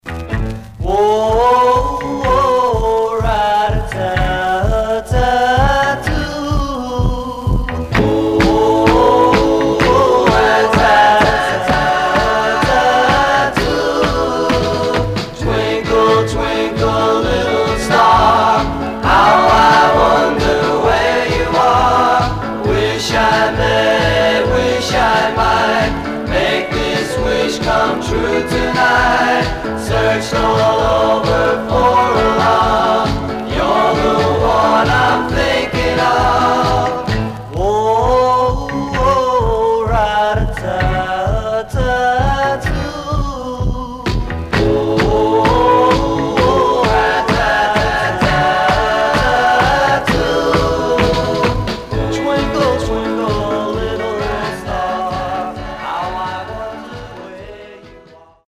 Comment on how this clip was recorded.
Condition Some surface noise/wear Stereo/mono Mono